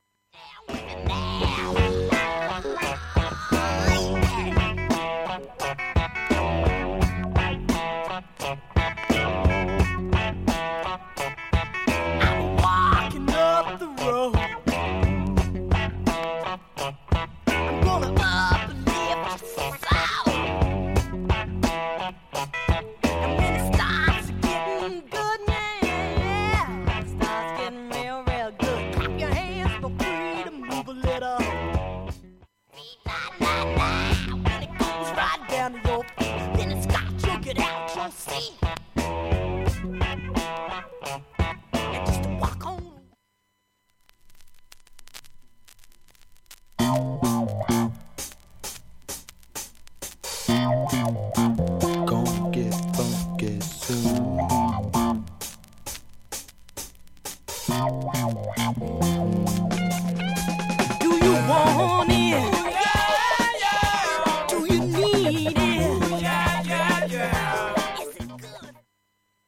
曲間無音部ごくかすかなチリツキ程度
普通に音質良好全曲試聴済み。
B-1イントロに軽いチリプツ出ます。
現物の試聴（上記）できます。音質目安にどうぞ